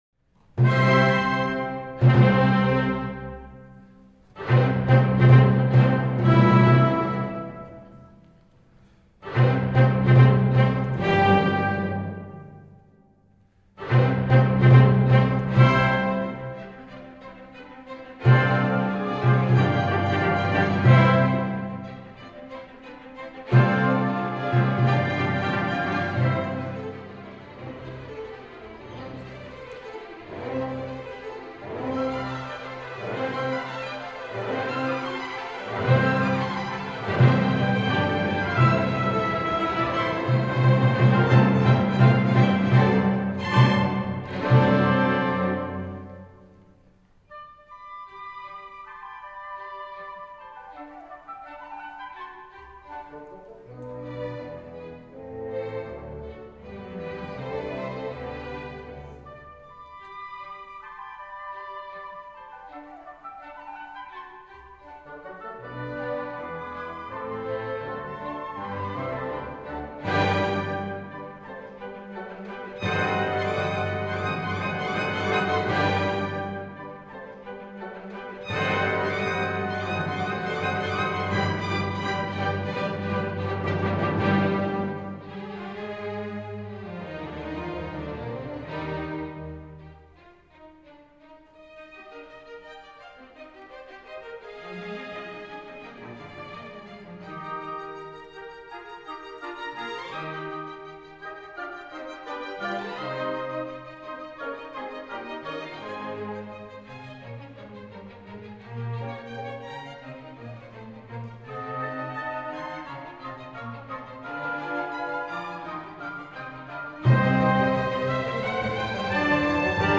Orchestra version
Orchestra  (View more Intermediate Orchestra Music)
Classical (View more Classical Orchestra Music)